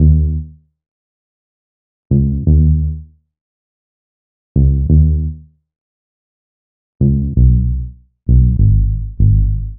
Hands Up - Muted Bass.wav